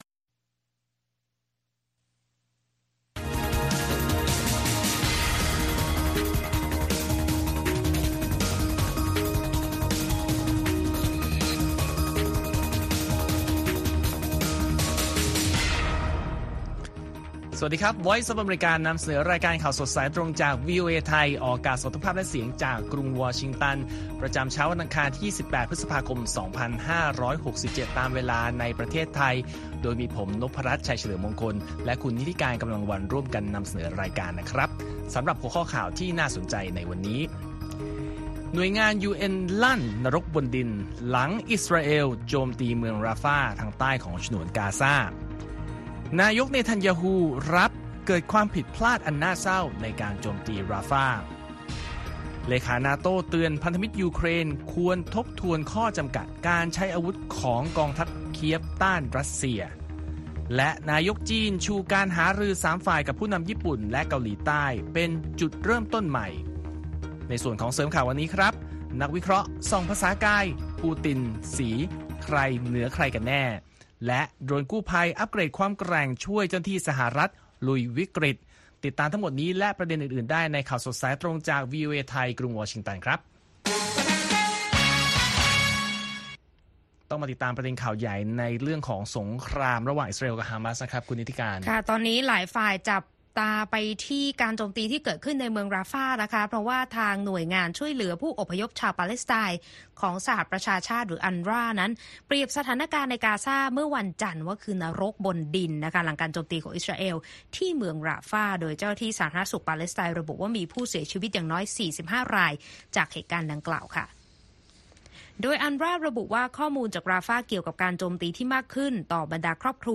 ข่าวสดสายตรงจากวีโอเอไทย 8:30–9:00 น. วันอังคารที่ 28 พฤษภาคม 2567